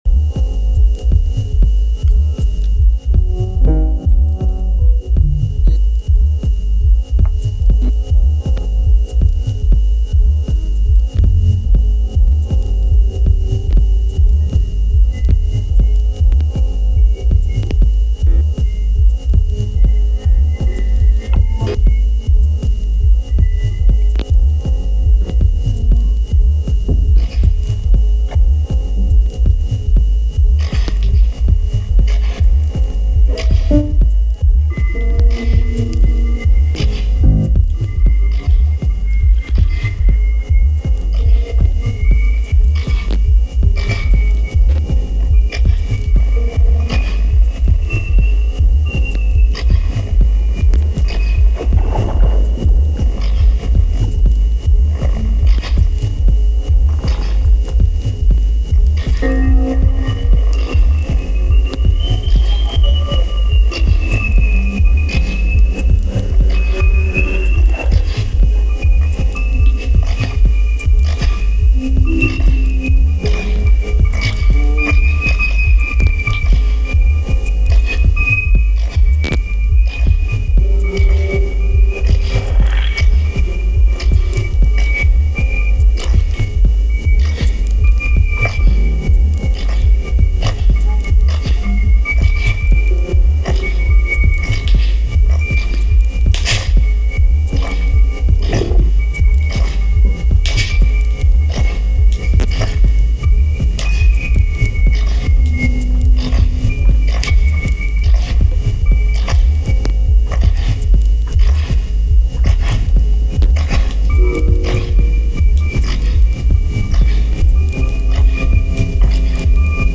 さらに抽象度を高めた物音パルス長編